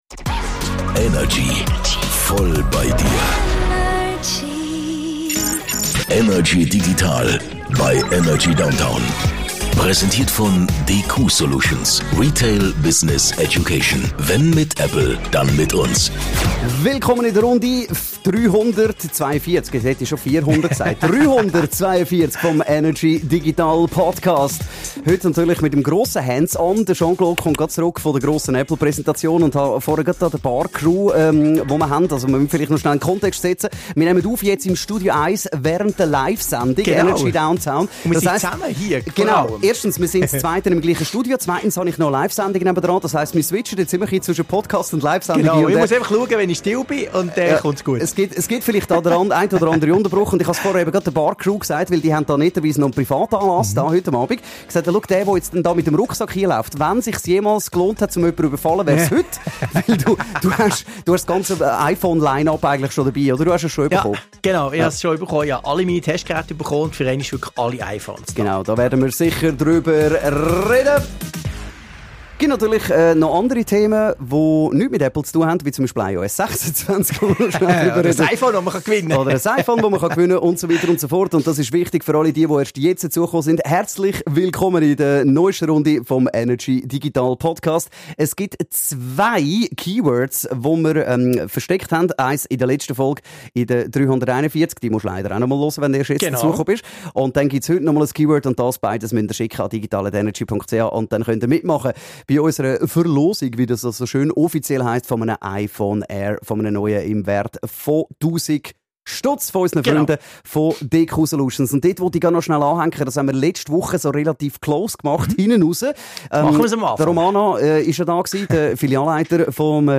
im Energy Studio über die digitalen Themen der Woche.